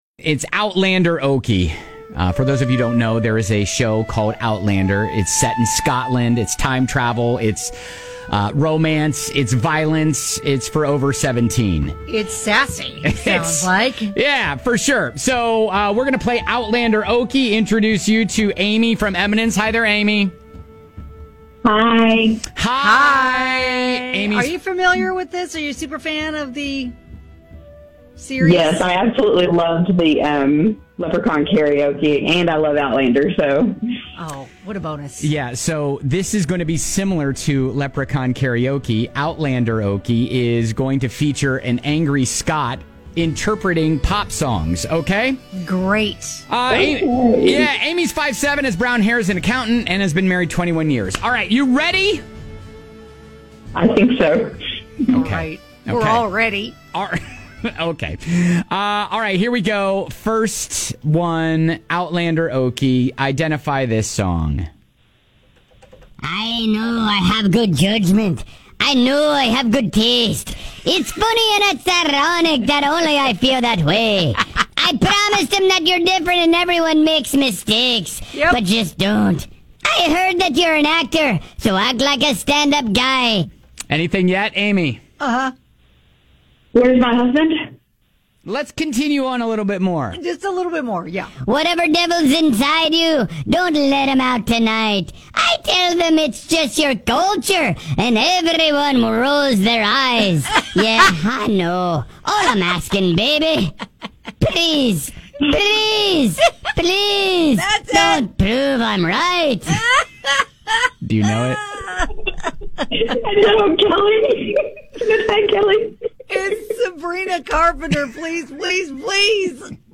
Can you guess the songs our angry Scot is singing?